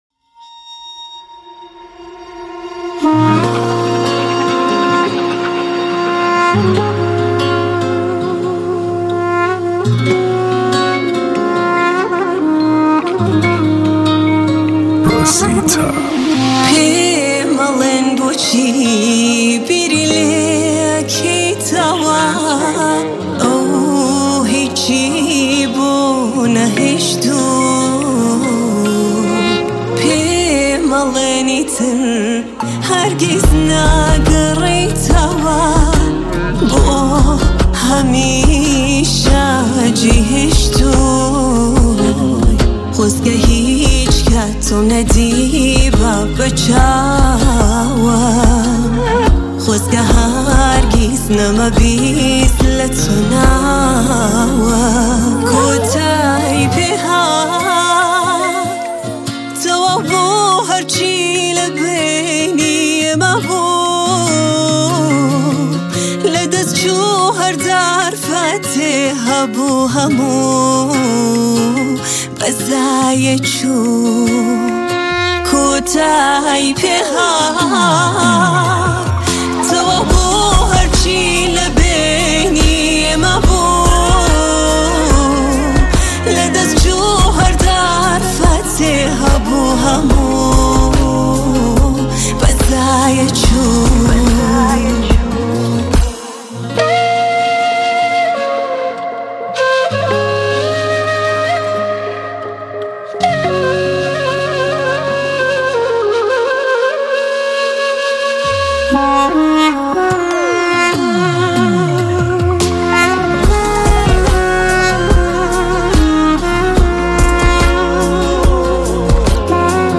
آهنگ کردی پاپ